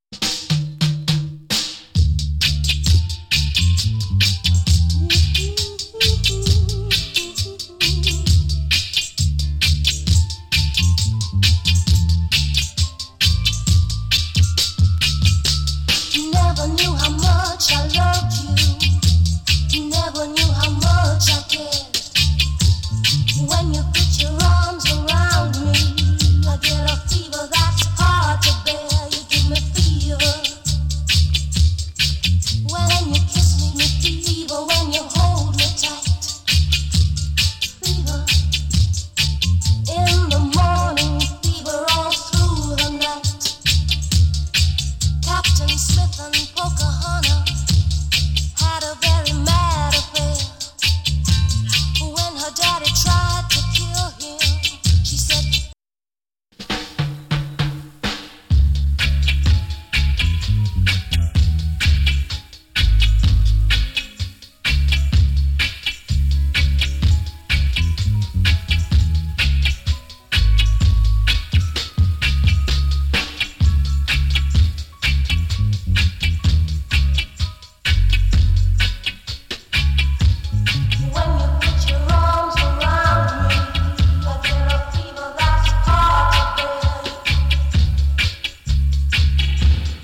KILLER FEMALE VOCAL !!